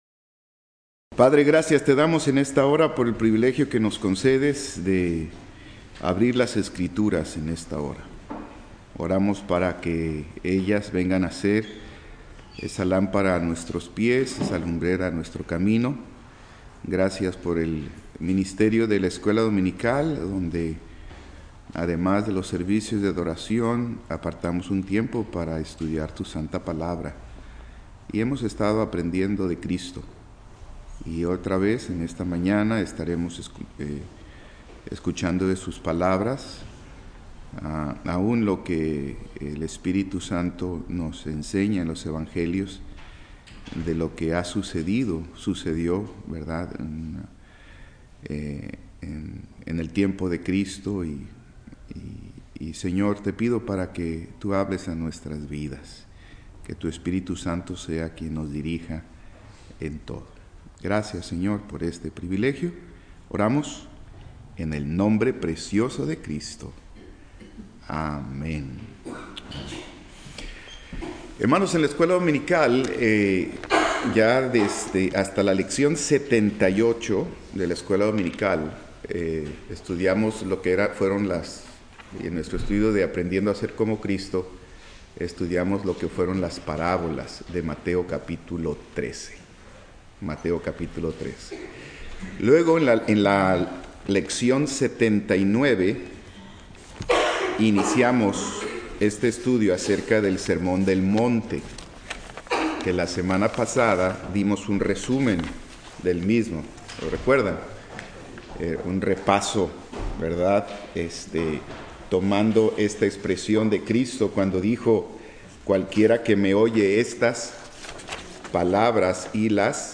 Escuela Dominical